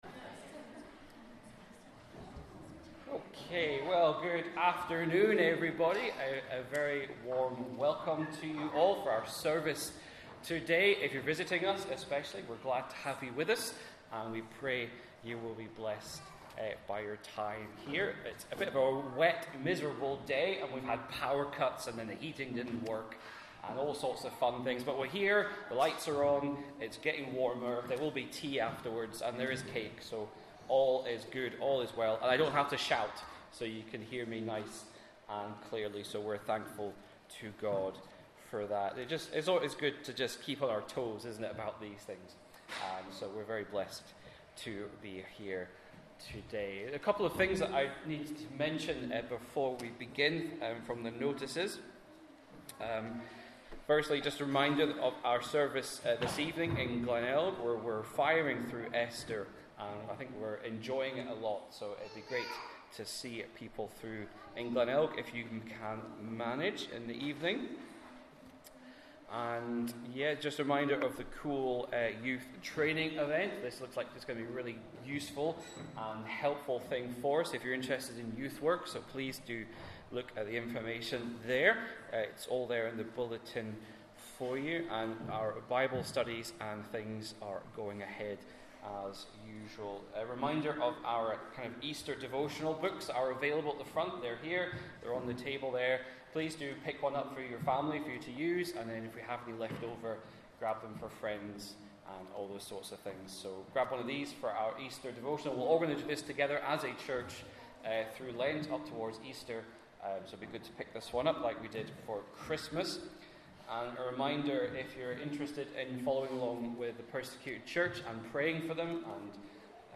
Genesis Passage: Genesis 6:8-22 Service Type: Inverinate AM Download Files Bulletin « Sleepless night